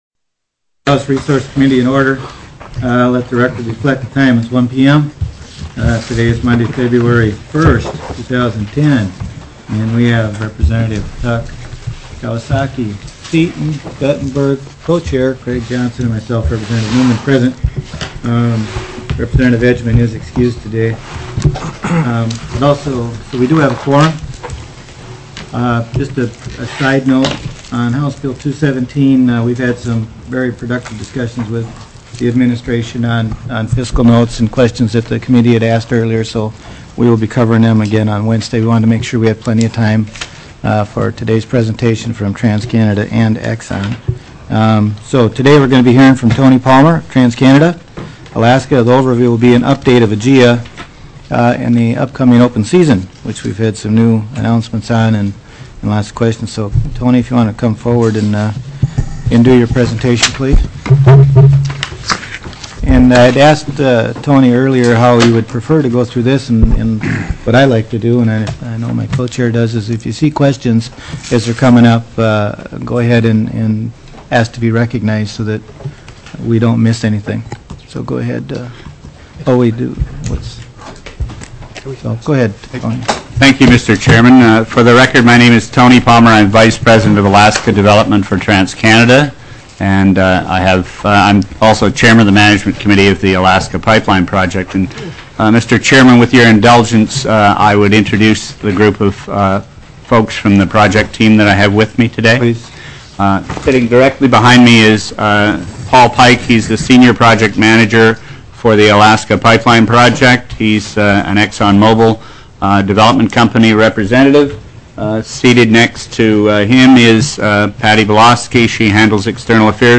02/01/2010 01:00 PM House RESOURCES
TELECONFERENCED Alaska: AGIA Update/Open Season